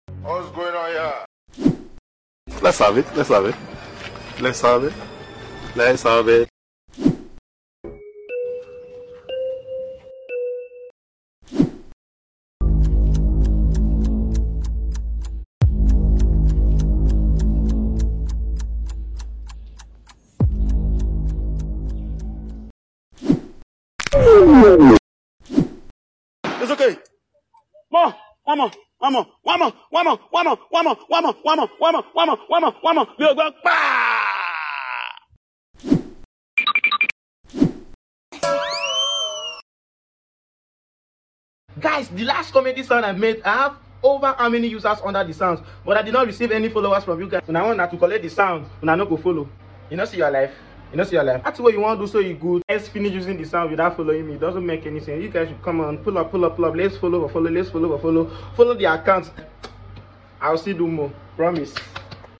Trending comedy sounds effects sound effects free download